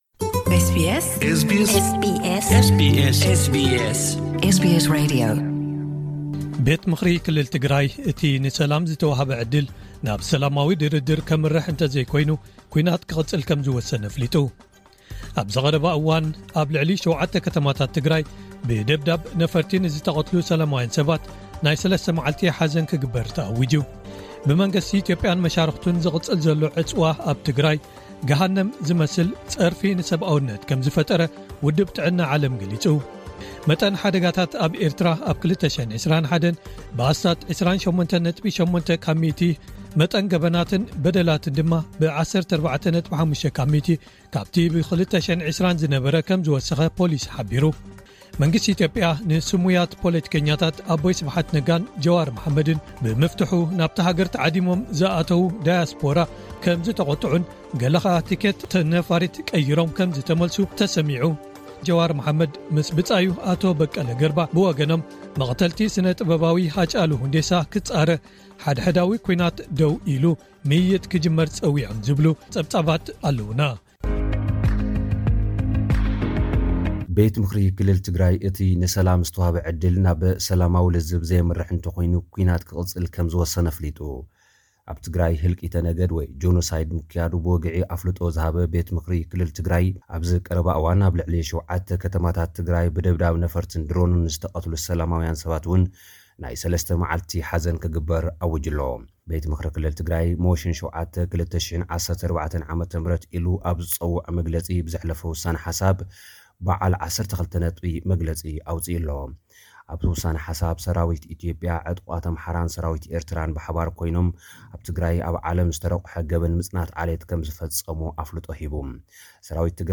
ሓጸርቲ ጸብጻባት ዜና፡